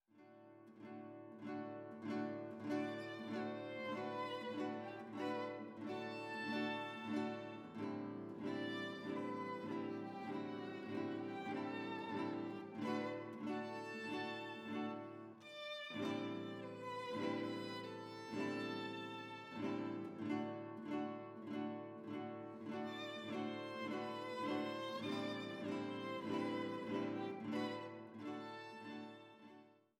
oft von der Folklore seiner Heimat inspiriert